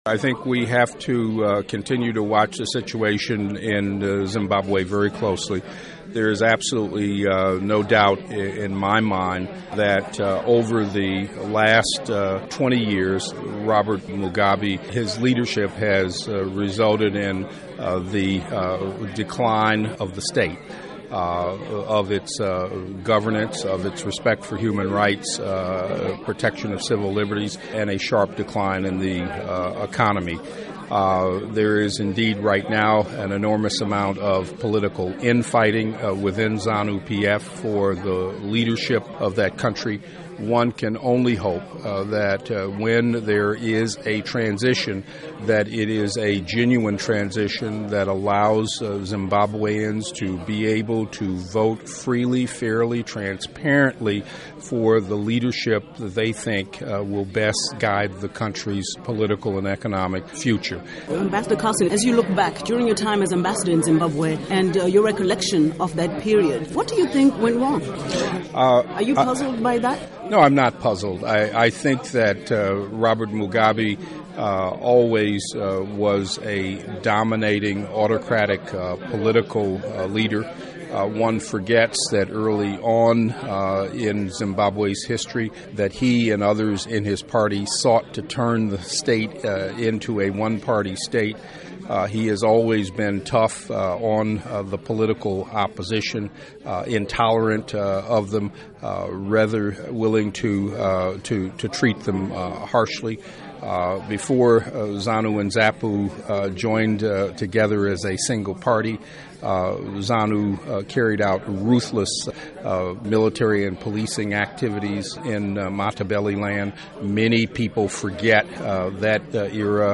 Interview With Ambassador Johnnie Carson on Zanu PF Factionalism